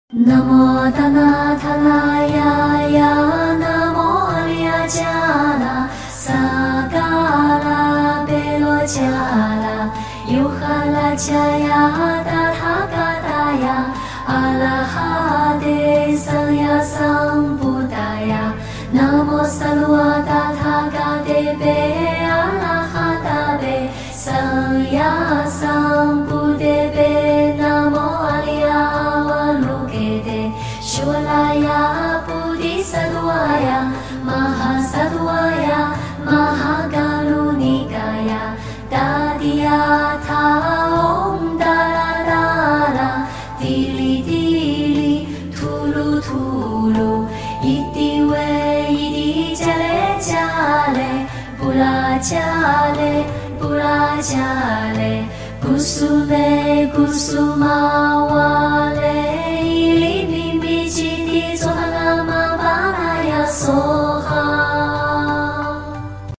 Healing music Great Compassion sound effects free download